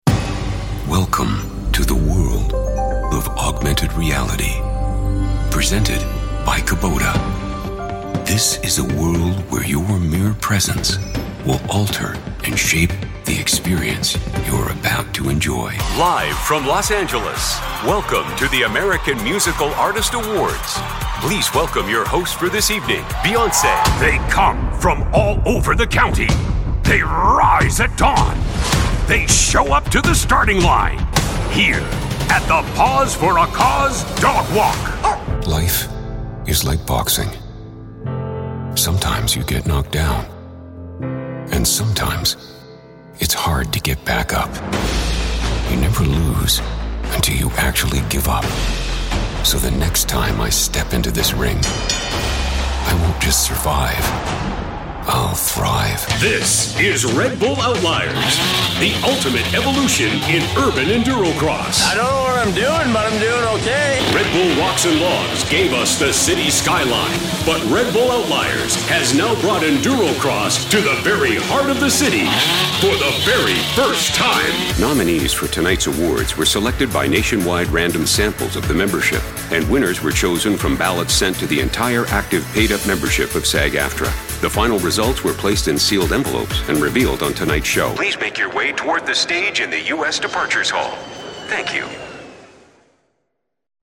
uma voz imponente de barítono canadense — autoritária, confiante e versátil.
Inglês (canadense)
Microfone Sennheiser MKH 416
Cabine de voz personalizada
Meia-idade
BarítonoGravesProfundo